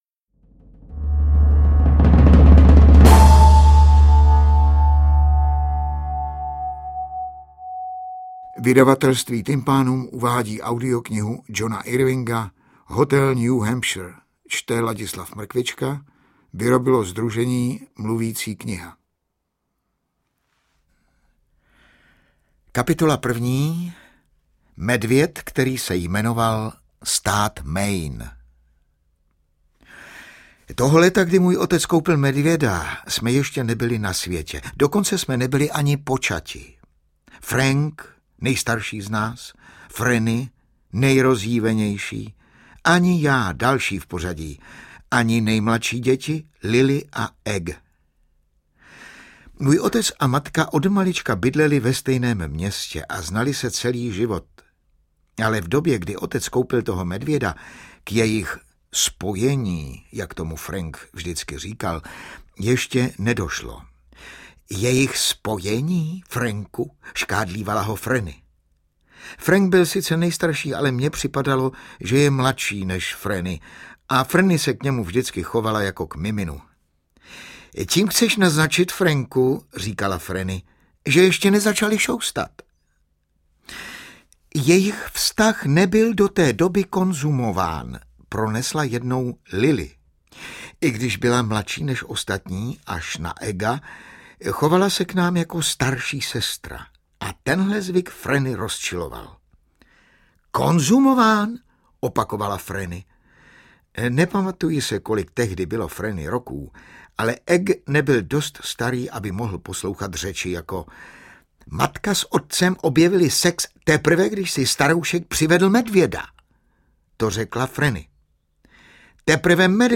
Interpret:  Ladislav Mrkvička
AudioKniha ke stažení, 126 x mp3, délka 21 hod. 31 min., velikost 1175,4 MB, česky